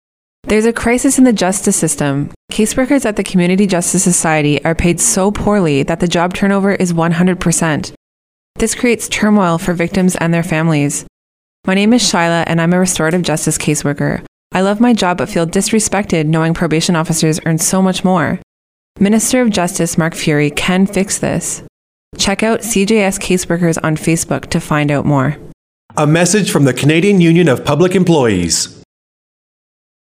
Members of CUPE 4764 recently launched two radio ads asking Minister of Justice Mark Furey to fix the disparity in wages for community restorative justice caseworkers.